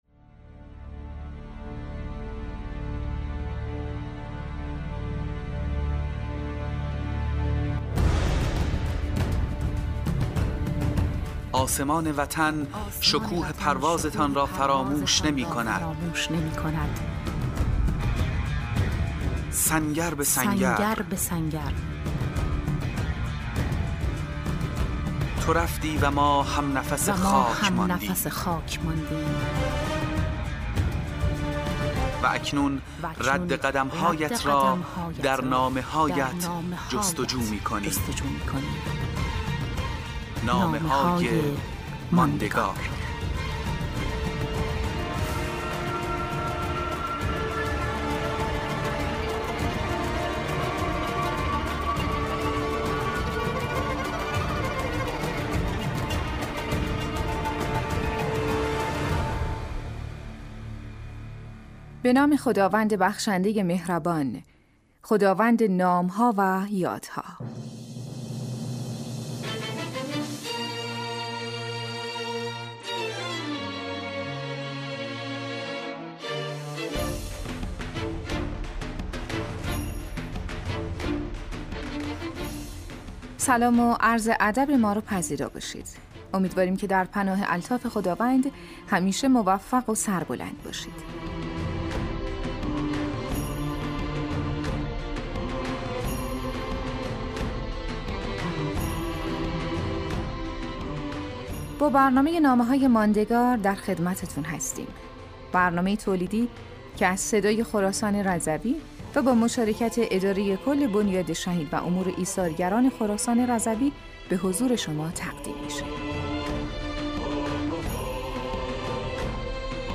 مجموعه 25 برنامه رادیویی از شهدای استان حراسان رضوی - شماره 8